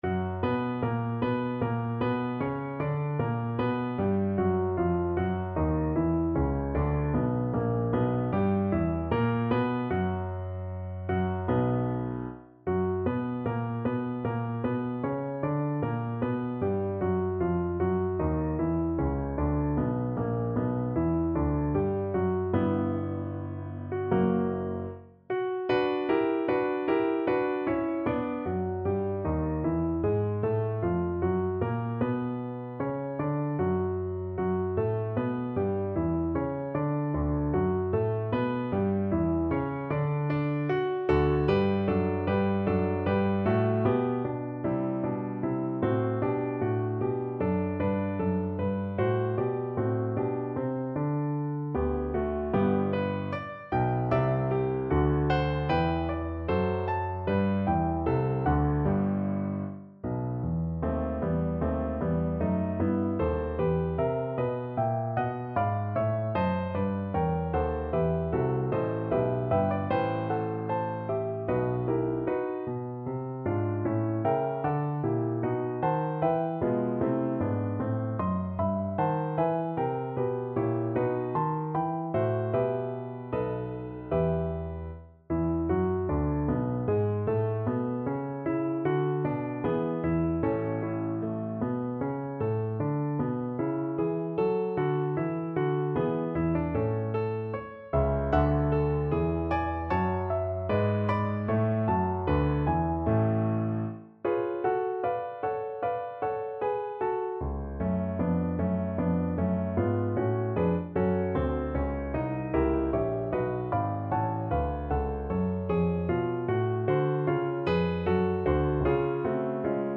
Play (or use space bar on your keyboard) Pause Music Playalong - Piano Accompaniment Playalong Band Accompaniment not yet available reset tempo print settings full screen
B minor (Sounding Pitch) (View more B minor Music for Bass Voice )
Larghetto (=76)
Classical (View more Classical Bass Voice Music)